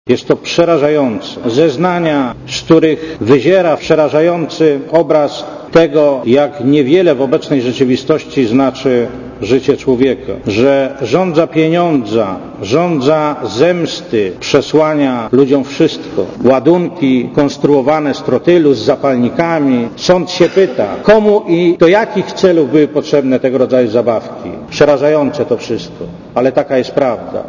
Komentarz audio (108kb)